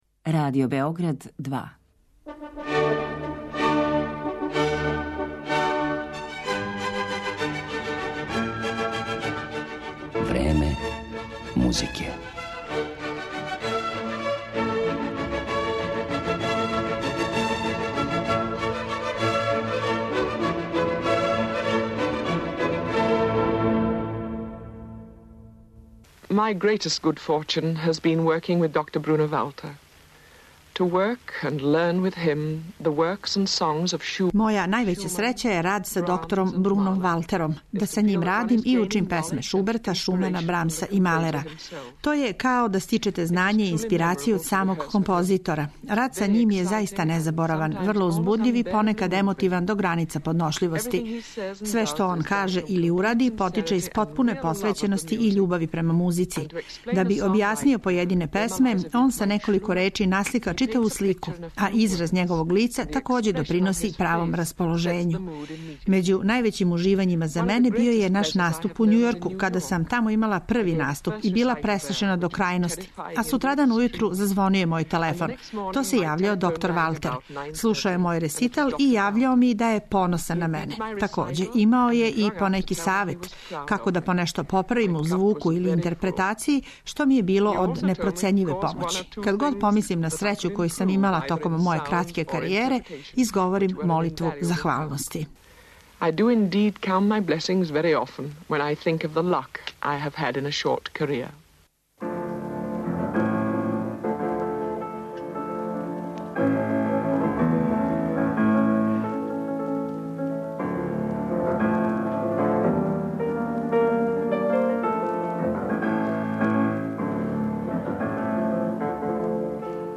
У емисији ће бити емитовани архивски снимци њених интерпретација Шубертових, Брамсових и Малерових песама.